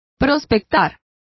Complete with pronunciation of the translation of prospect.